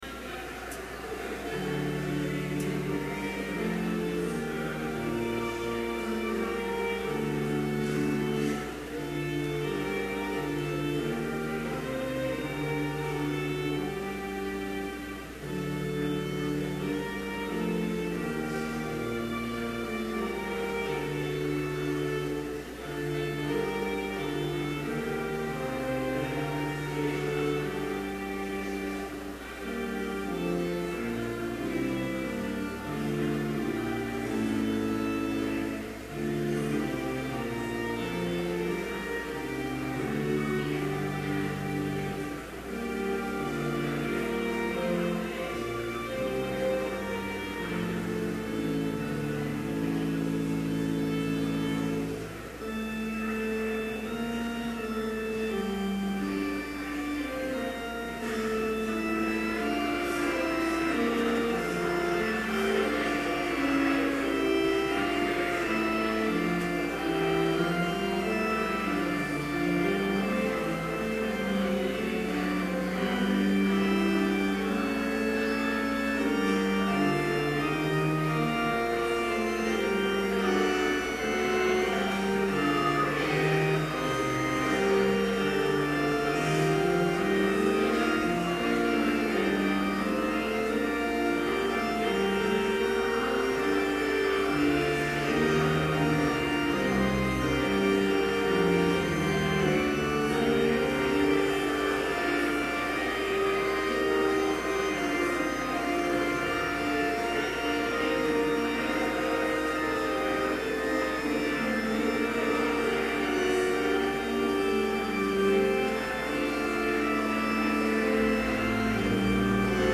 Complete service audio for Chapel - September 9, 2011
Order of Service Prelude Hymn 418, vv. 1-3, How Faith the Church of Christ Shall Stand Scripture Reading: Philippians 2:1-3 Homily Prayer Hymn 418, vv. 4, 5 & 7, So let your tongue... Benediction Postlude